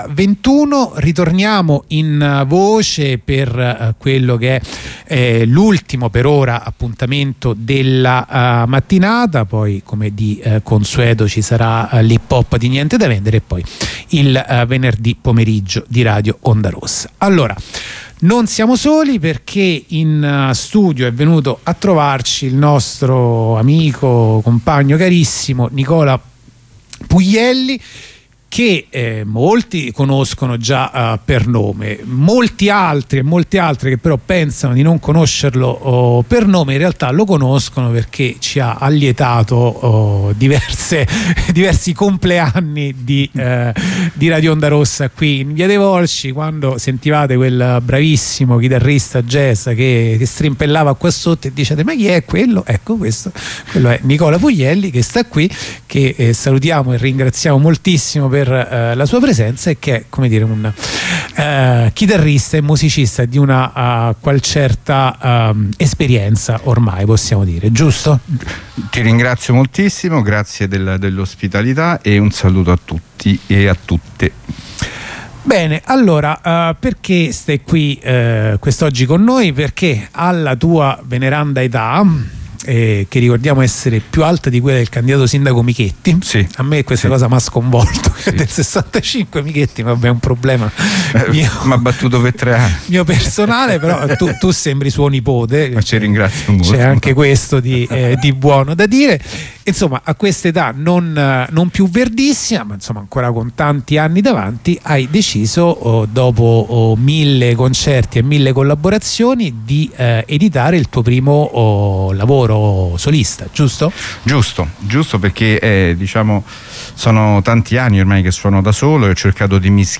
h 15:20 collegamento dalla nuova occupazione di bologna